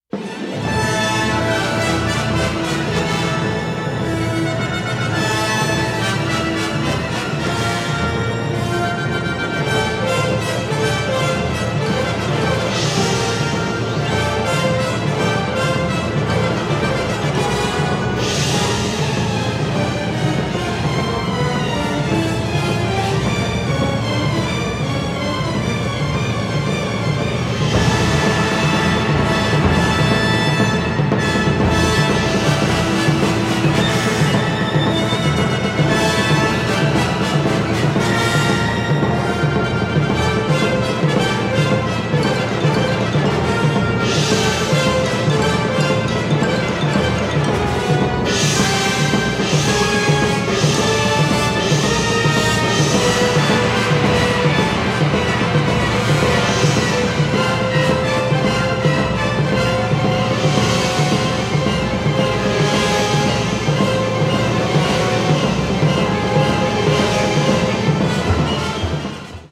a rerecording of music